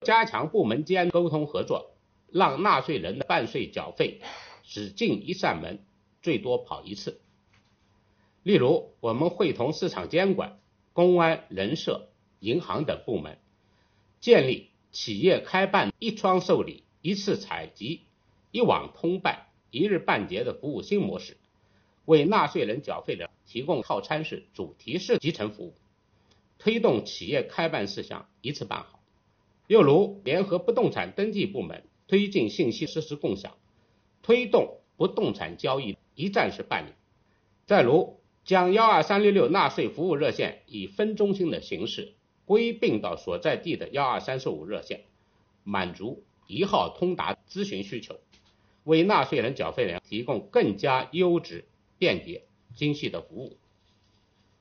近日，国家税务总局召开新闻发布会，就税收大数据反映经济发展情况、税务部门学党史办实事扎实推进办税缴费便利化、打击涉税违法犯罪等内容进行发布并回答记者提问。会上，国家税务总局纳税服务司司长韩国荣介绍了“我为纳税人缴费人办实事暨便民办税春风行动”取得的进展和成效。